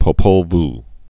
(pō-pōl v)